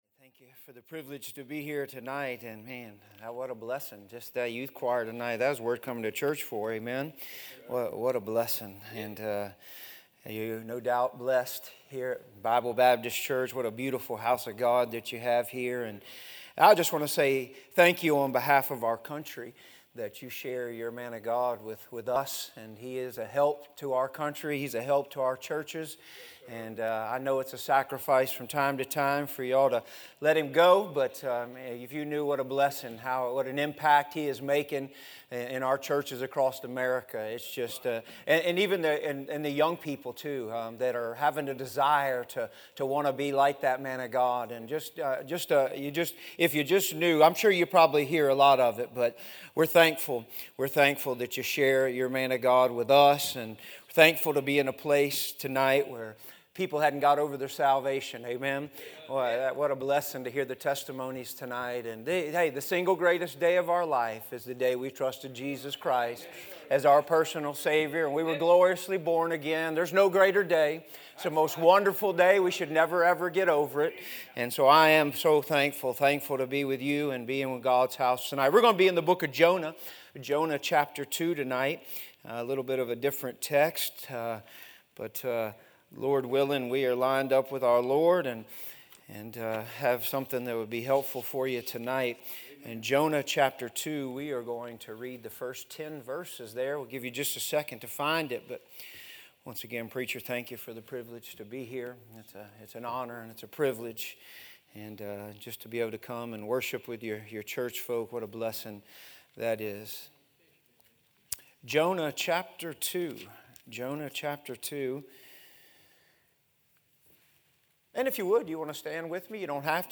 A sermon preached Thursday afternoon during our Spring Jubilee, on March 28, 2024.